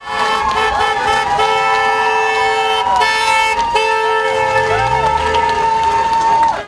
Do marszu przyłączali się przechodnie, w sumie główną arterią miasta szło kilkaset osób.
Wielu kierowców w geście poparcia dla protestu zjeżdżało na bok, wciskało klaksony i unosiło kciuki do góry.
Klaksony-clip-5.mp3